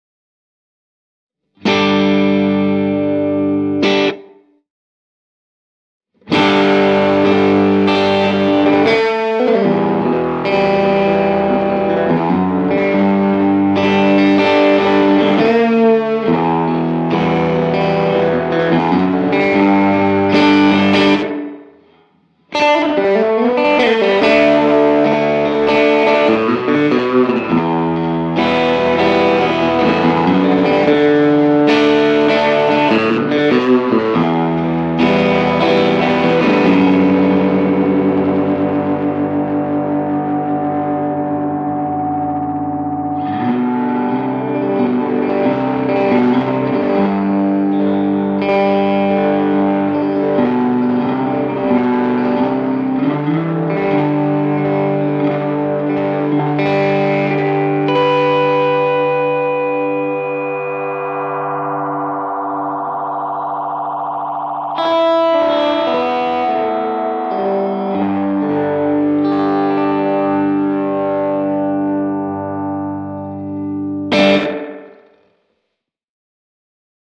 Dirty tape sounds paired with a short decay time help create a grungy, unique slap-back sound with vibe for days.